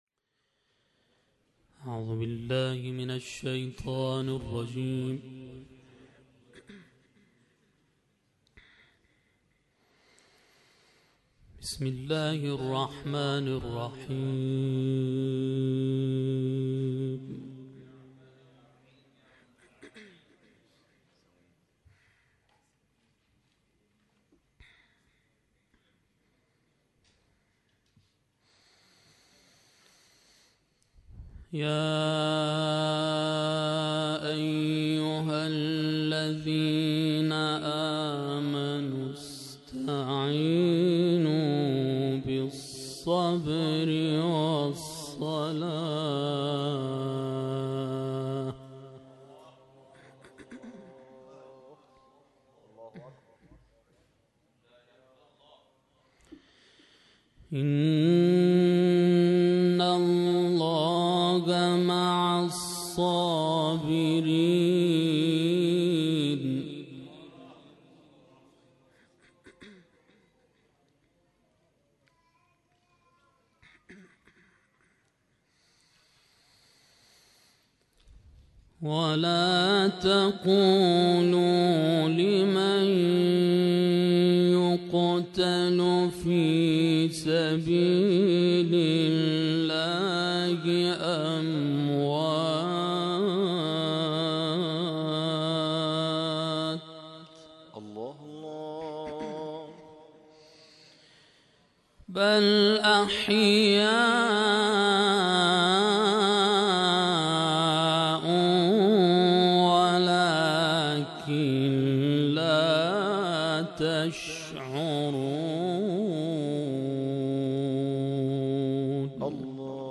پانزدهمین احیای قرآنی شب عاشورا از سوی مجمع قاریان شهرری برگزار شد + عکس
مسجد شیخ صدوق شهر ری، شب گذشته و در آستانه عاشورای حسینی میزبان جمع کثیری از قاریان، حافظان و دوستداران قرآن بود که در قالب محفل انس با قرآن، به احیای شب عاشورا پرداختند.
تلاوت